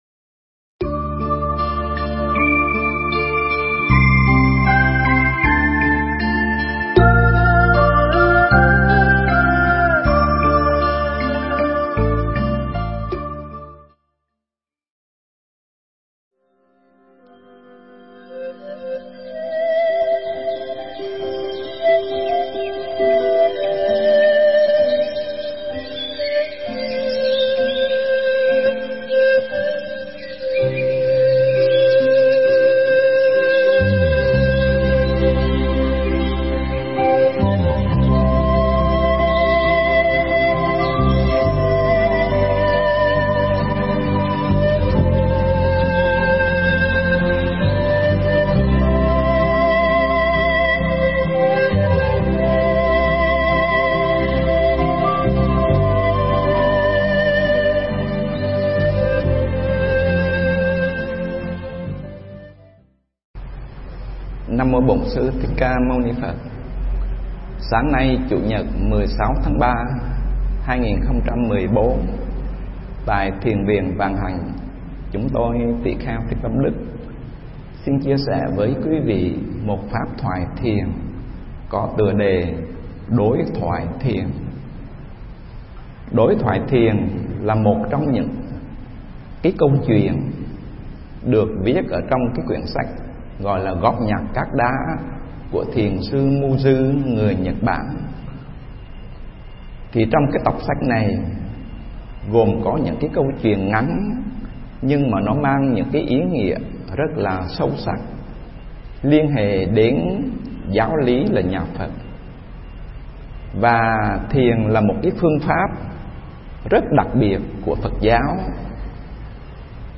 Mp3 Thuyết pháp Đối Thoại Thiền